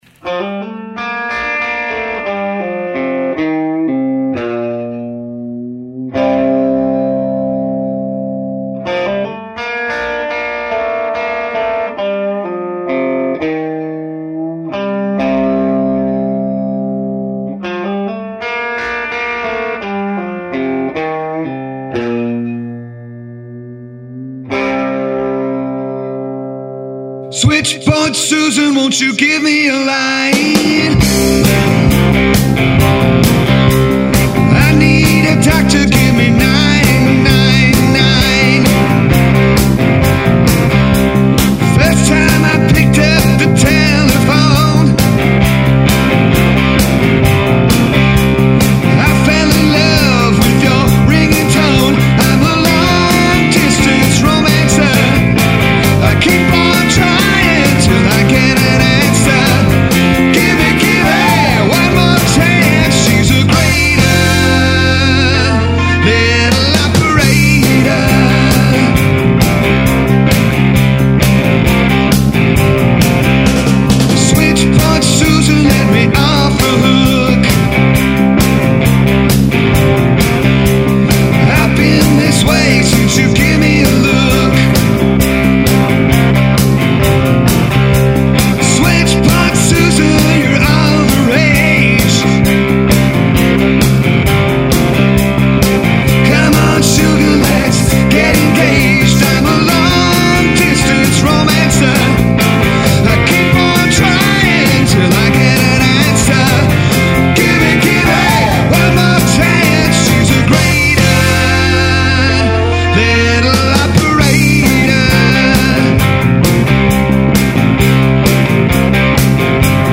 Almost all original local rock band.
intro guitar, riffs throughout, backup vocals